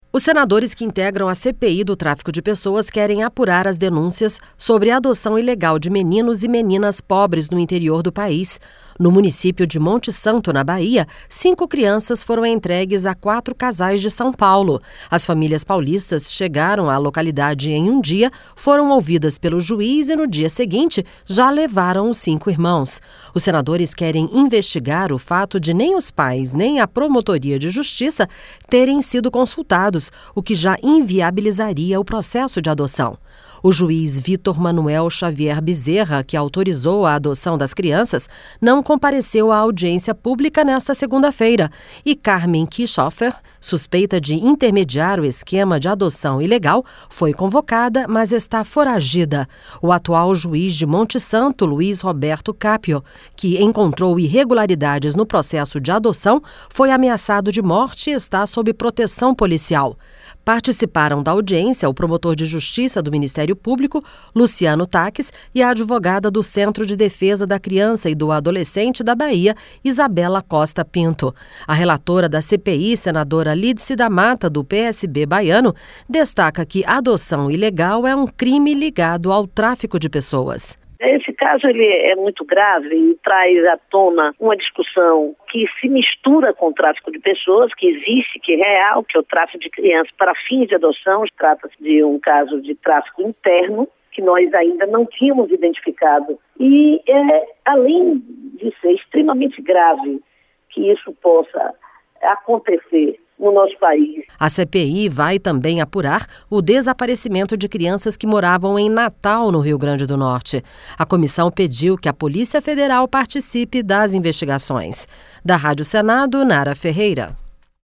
A relatora da CPI, senadora Lídice da Mata, do PSB baiano, destaca que adoção ilegal é um crime ligado ao tráfico de pessoas: (LÍDICE DA MATA) Esse caso é muito grave e traz a tona uma discussão que se mistura ao tráfico de pessoas, que existe, que é real, que é o tráfico de crianças para o fim de adoção.